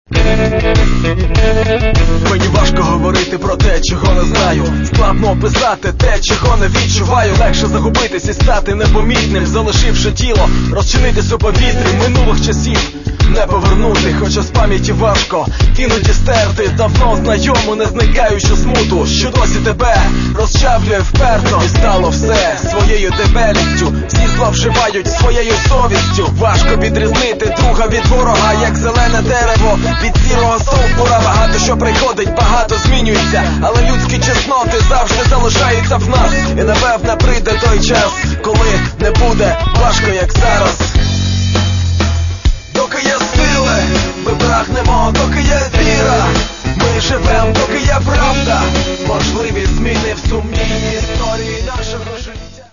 Каталог -> Хіп-хоп